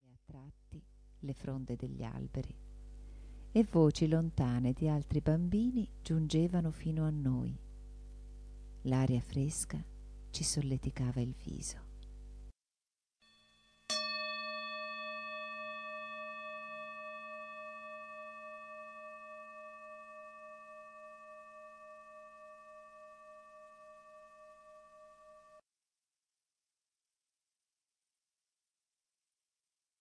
Meditazione guidata: Semina e pacciamatura